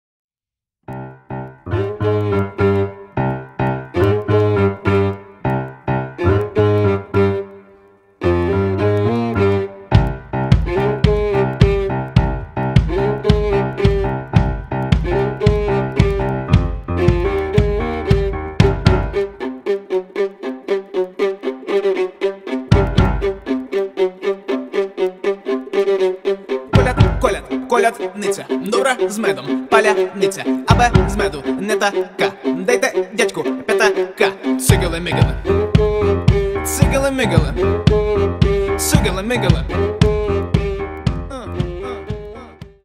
• Качество: 320, Stereo
клавишные
виолончель